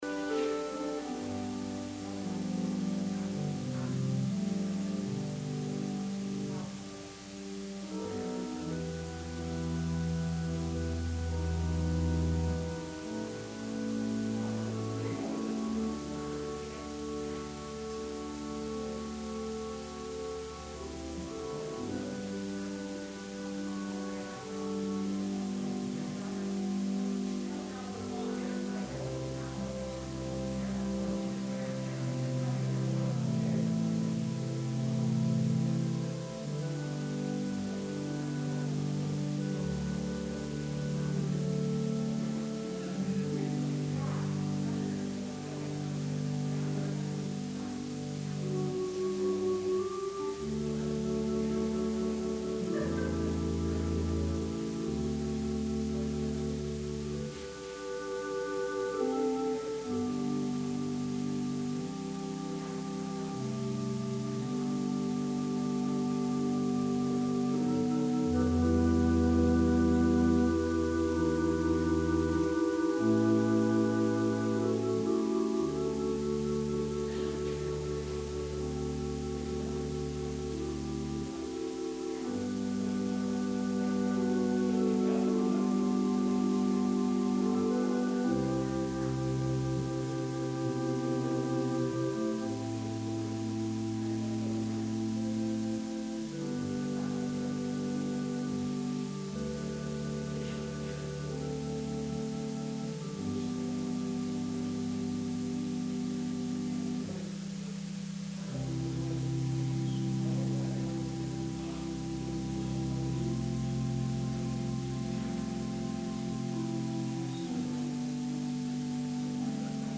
Sermon – November 15, 2020